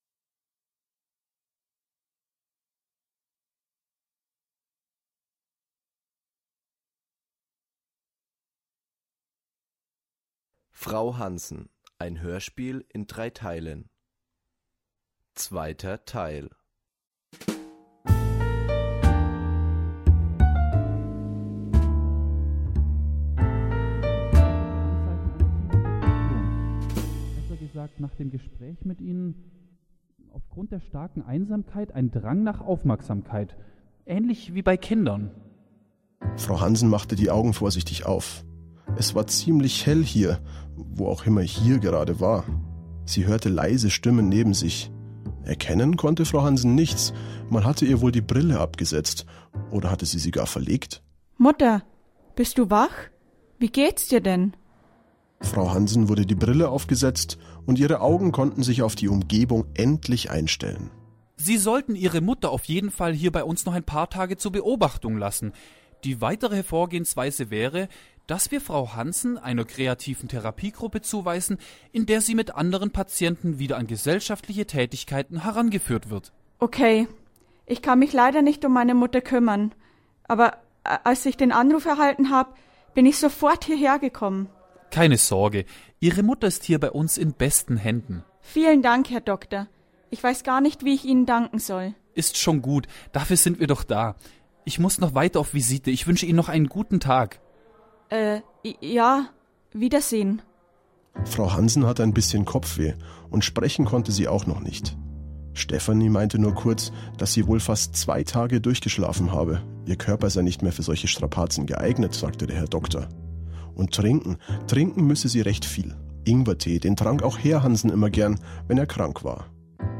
Hoerspiel_FrauHansen_Folge2.mp3